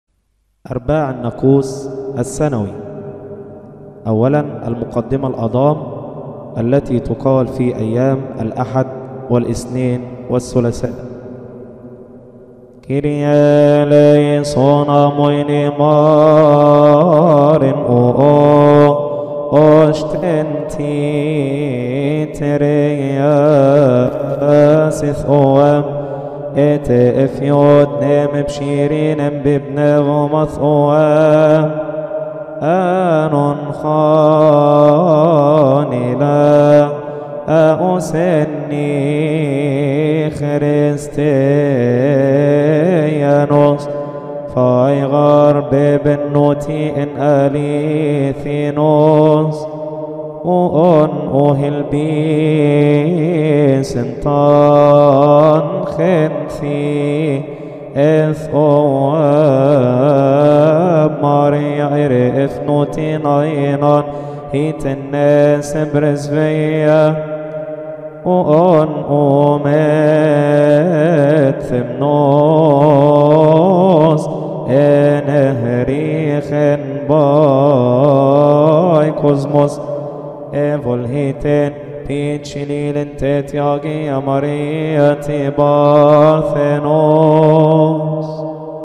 المرتل